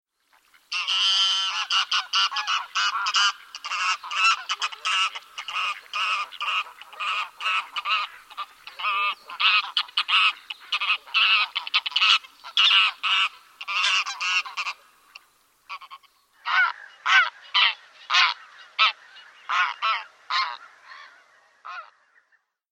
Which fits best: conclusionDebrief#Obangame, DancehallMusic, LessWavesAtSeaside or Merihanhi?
Merihanhi